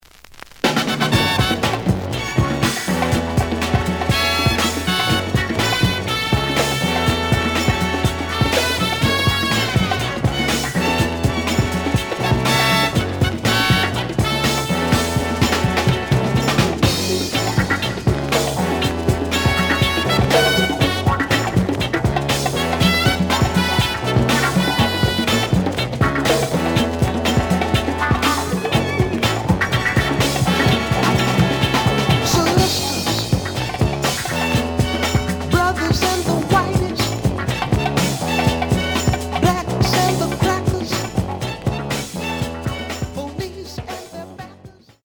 The audio sample is recorded from the actual item.
●Genre: Funk, 70's Funk